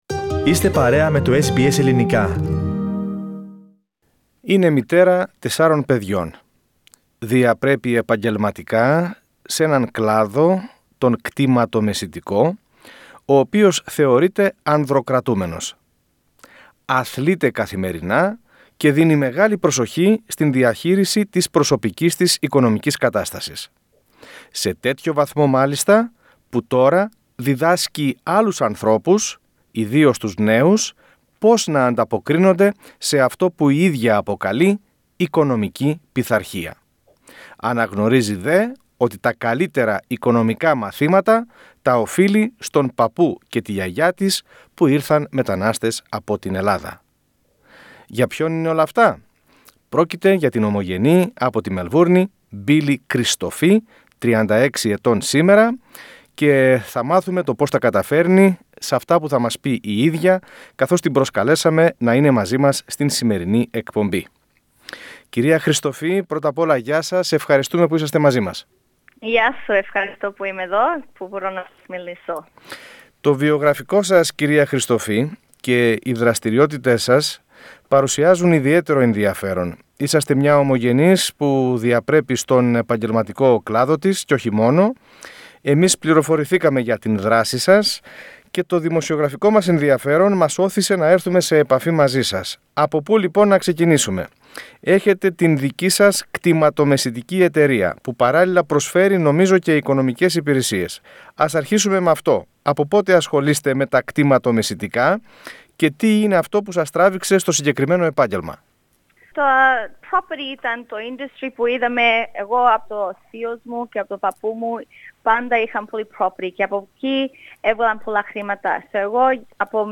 μιλά στο Ελληνικό Πρόγραμμα για την καριέρα της.